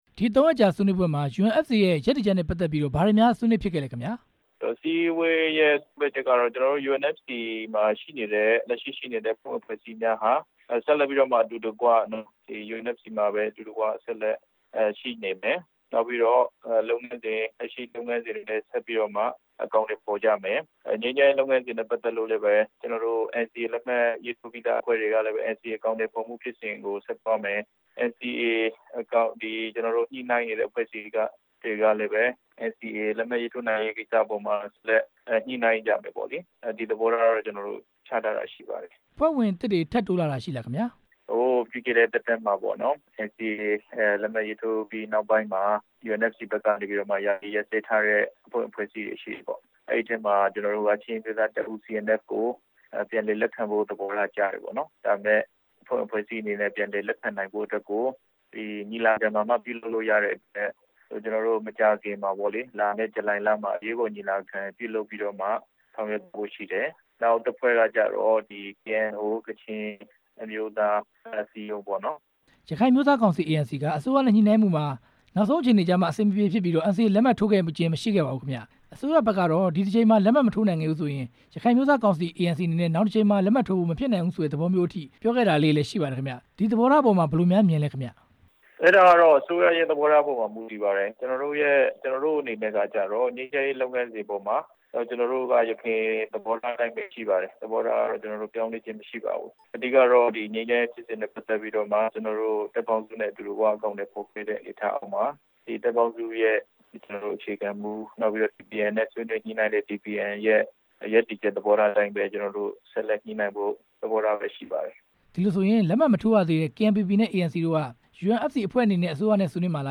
မေးမြန်းချက်
ဆက်သွယ်မေးမြန်းထားပါတယ်။